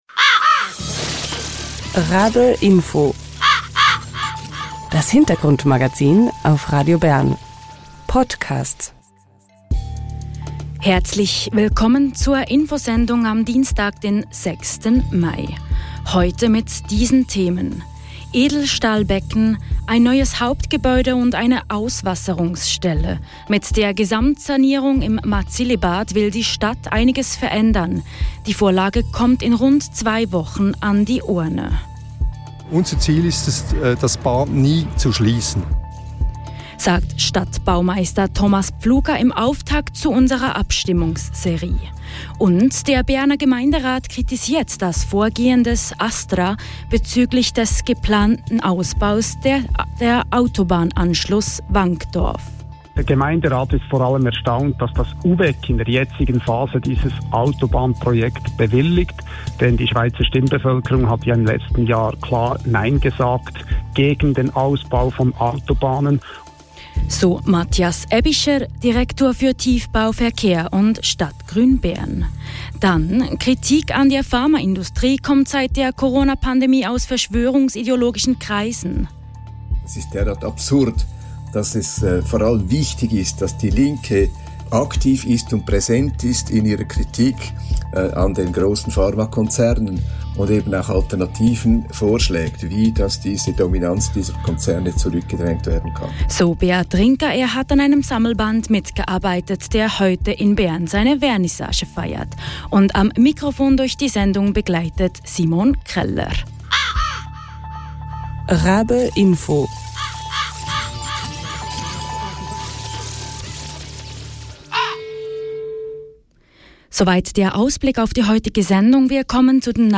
Das RaBe-Info startet mit der Sanierung des Marzili-Bad die Abstimmungsserie. Der Berner Gemeinderat kritisiert das Vorgehen des ASTRA bezüglich des geplanten Ausbaus des Autobahnanschluss Wankdorf. Weshalb erkärt Matthias Aebischer, Direktor für Tiefbau, Verkehr und Stadtgrün Bern im Interview.